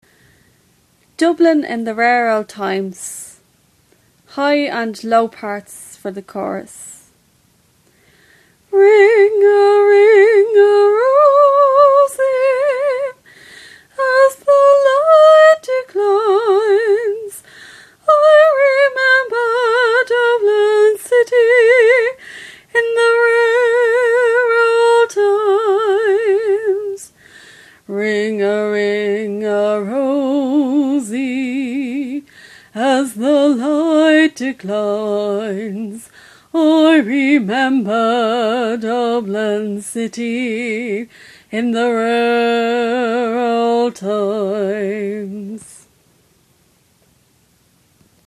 Harmony